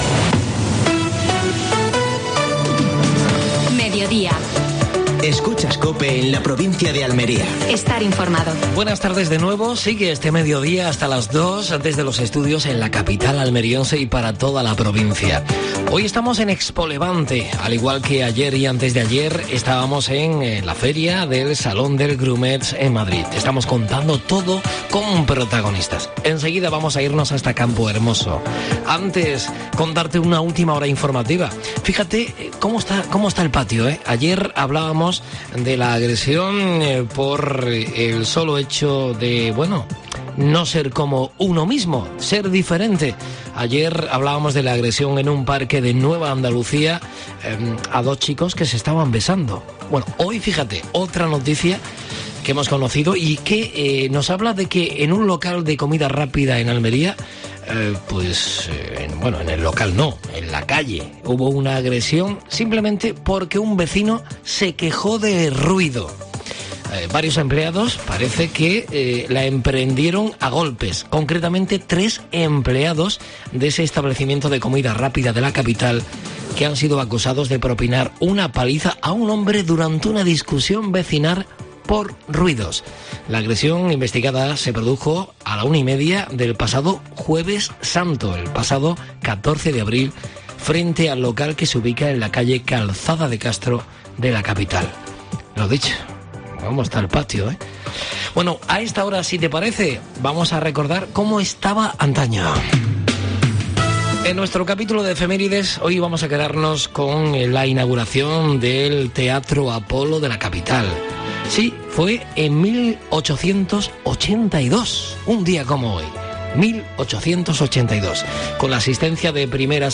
AUDIO: Actualidad en Almería. Primera jornada de ExpoLevante. Entrevista